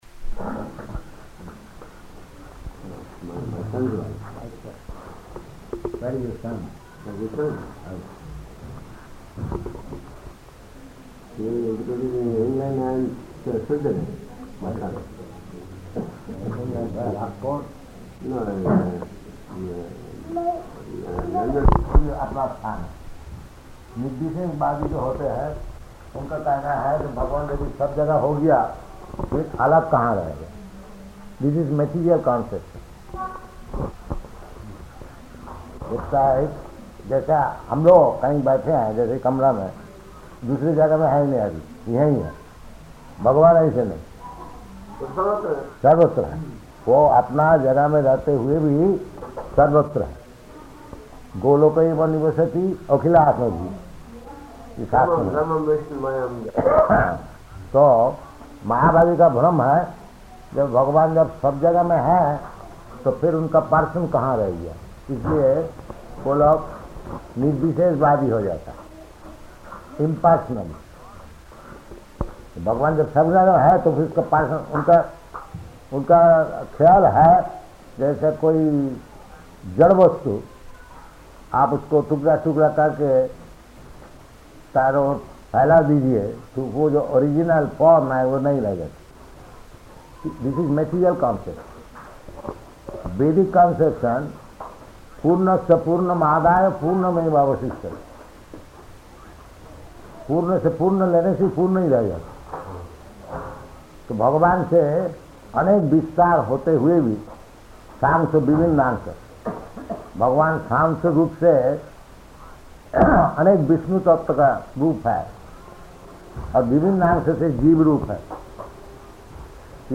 Room Conversation
Room Conversation --:-- --:-- Type: Conversation Dated: December 14th 1970 Location: Indore Audio file: 701214R2-INDORE.mp3 Prabhupāda: [to Indian man] Where is your son?